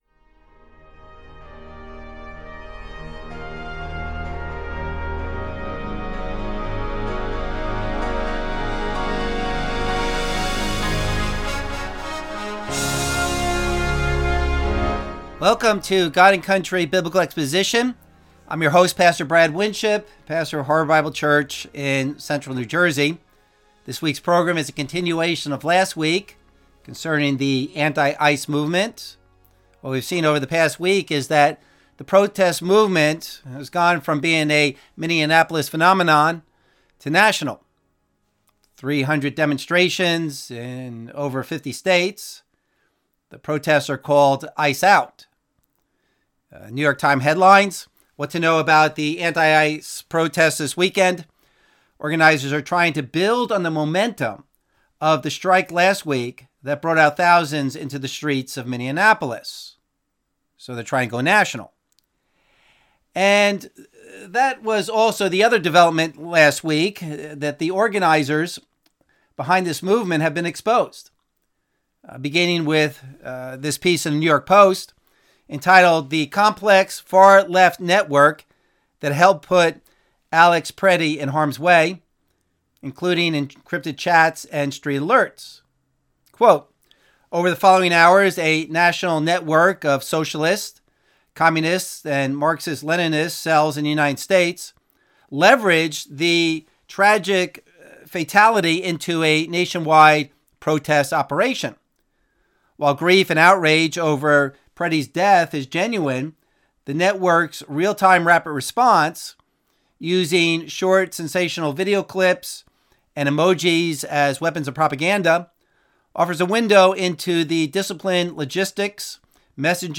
Abridged Radio Program